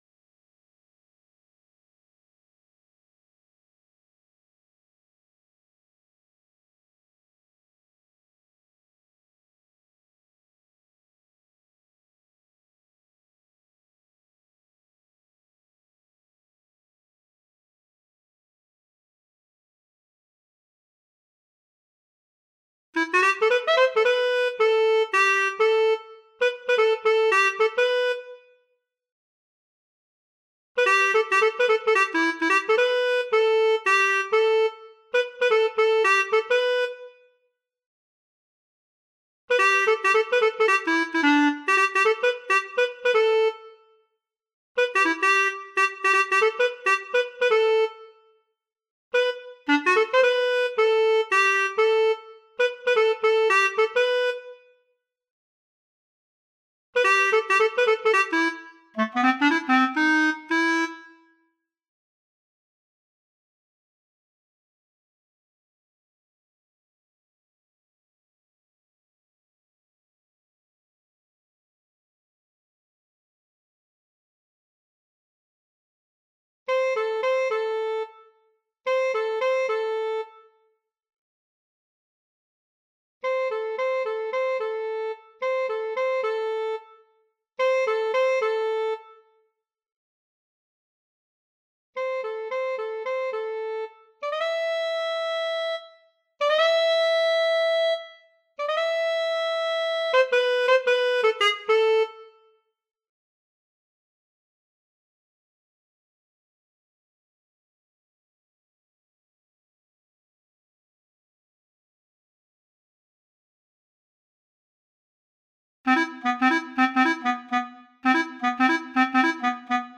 Sing3-v1-Clarinet.mp3